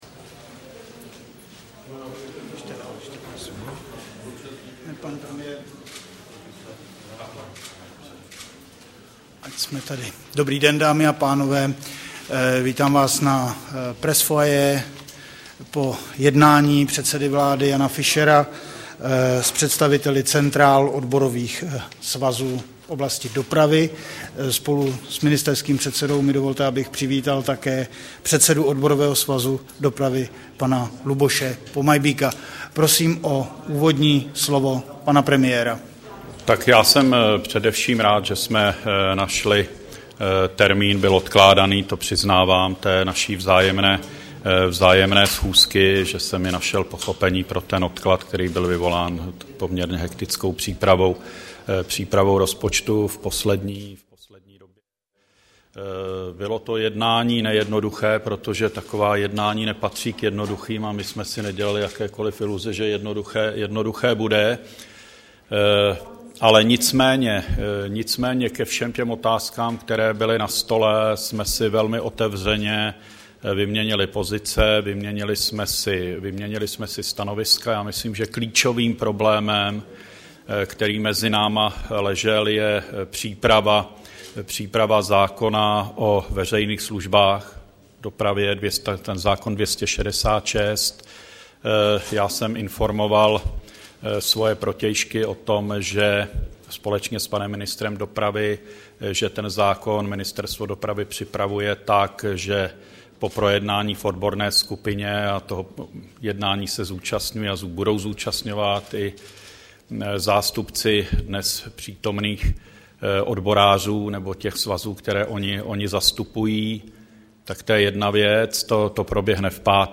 Tiskový brífink po jednání předsedy vlády ČR se zástupci Odborového svazu železničářů